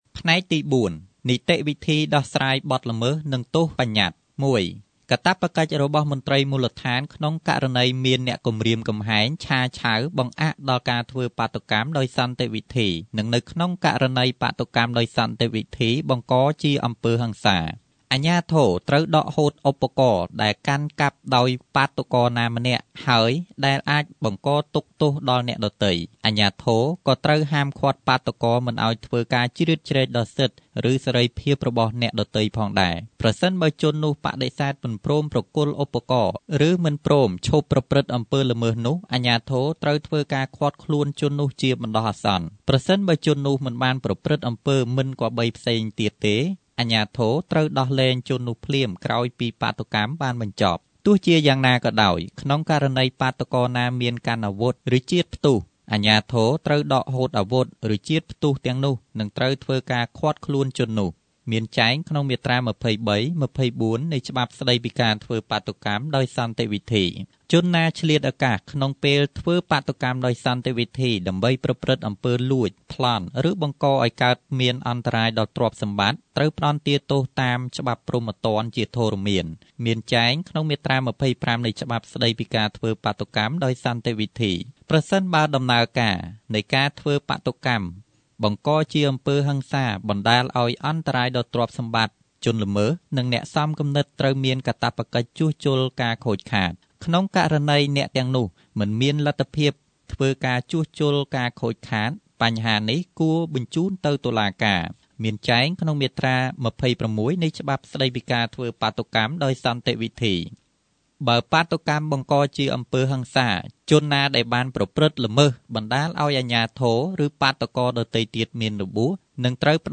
analyzed the Business and Human Right project at Beehive Radio FM 105 MHz. The purpose of Business and Human Rights is to protect and encourage human rights on businesses that abuse labour rights.